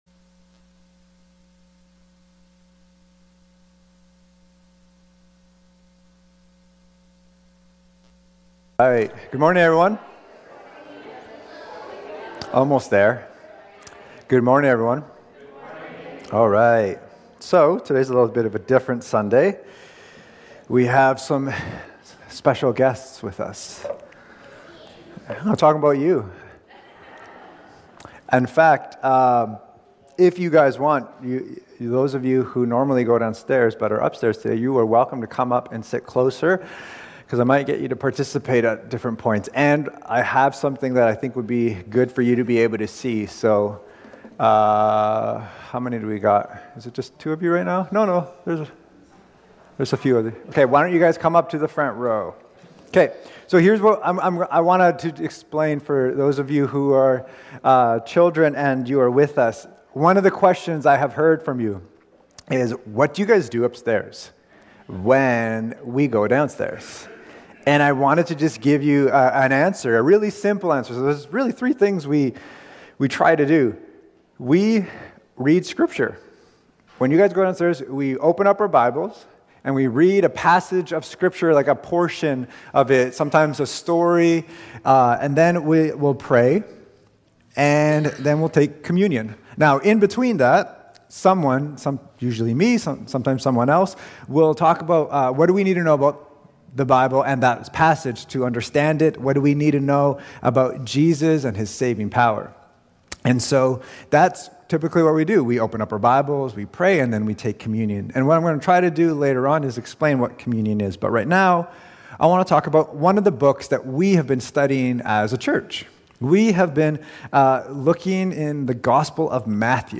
Family Service Sunday | Matthew 18:1-5 | Cascades Church
Cascades Church Sermons